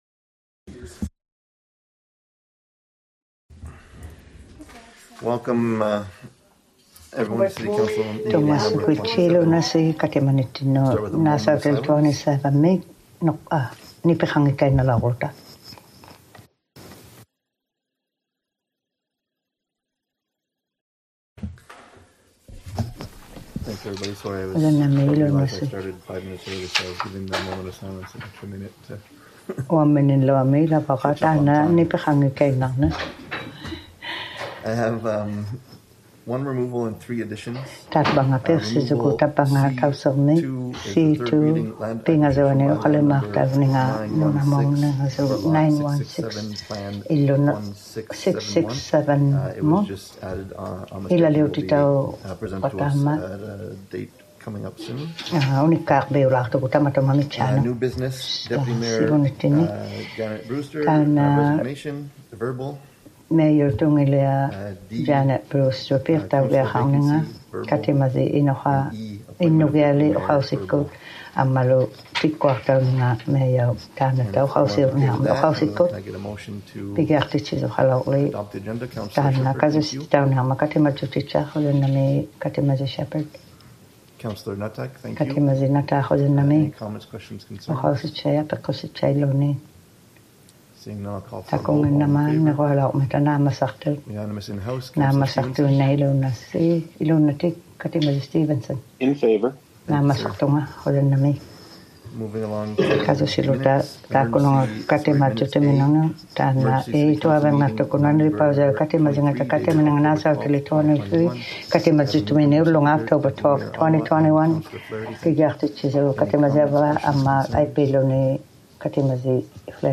ᓄᓇᓕᐸᐅᔭᒃᑯᑦᑦ ᑲᑎᒪᔨᖕᒋᑦᑕ ᑲᑎᒪᓂᖕᒐᑦ #27 - City Council Meeting #27 | City of Iqaluit
city_council_meeting_27_inuk.mp3